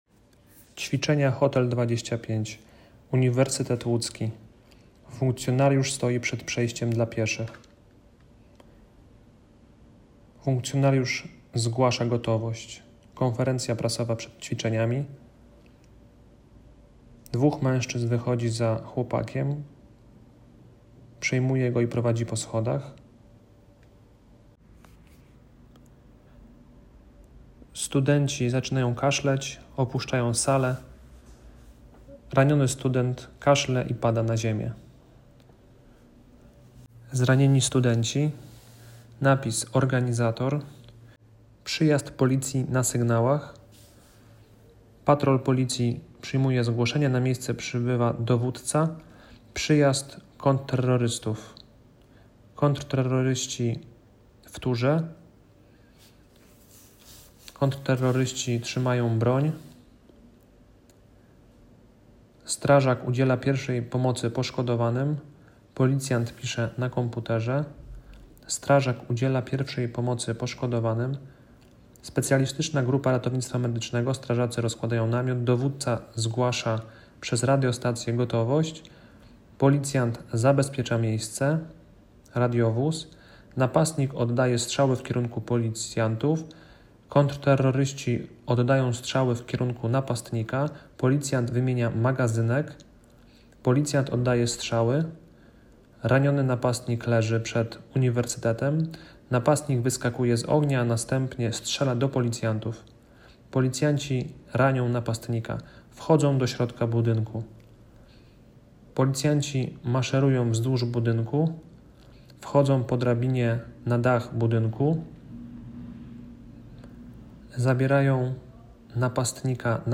Nagranie audio Audiodeskrypcja_2.m4a